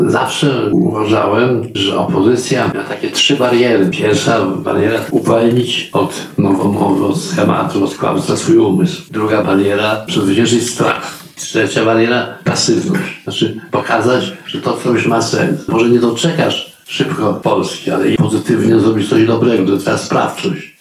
Wczoraj (17.03) w Małej Sali Widowiskowej ACKiM UMCS Chatka Żaka przeprowadzono spotkanie z Adamem Michnikiem, redaktorem naczelnym ,,Gazety Wybroczej”, kawalerem Orderu Orła Białego.
Adam Michnik – mówi Adam Michnik.